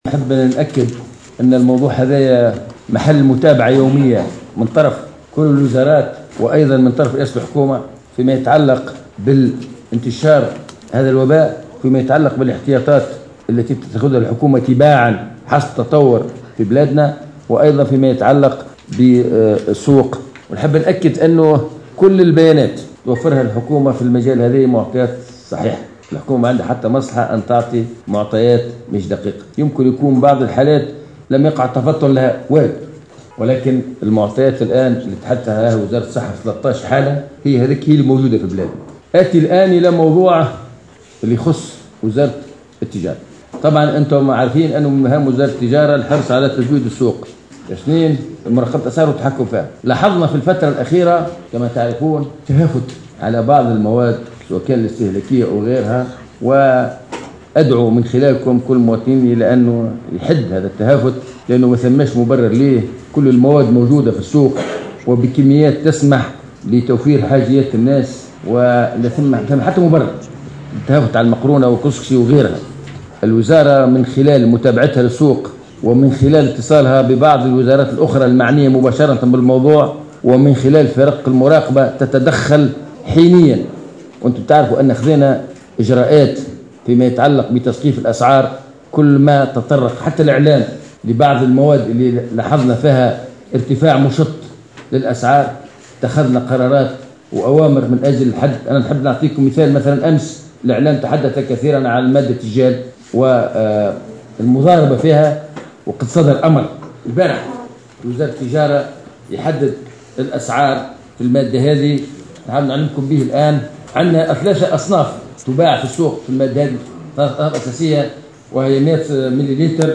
وشدد وزير التجارة في تصريح للجوهرة أف أم، على أن كل المواد الإستهلاكية والطبيّة وشبه الطبيّة متوفرة، و أن الحكومة حريصة على تزويد السوق بإنتظام، داعيا إلى عدم التهافت واللهفة "غير المبررة " وفق تعبيره.